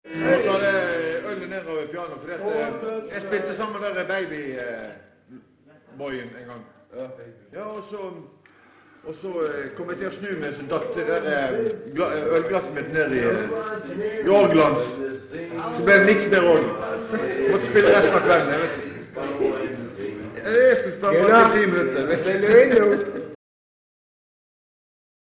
Her har vi et amatøropptak
Varierende og allsidig fest og danse -musikk.